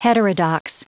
Prononciation du mot heterodox en anglais (fichier audio)
Prononciation du mot : heterodox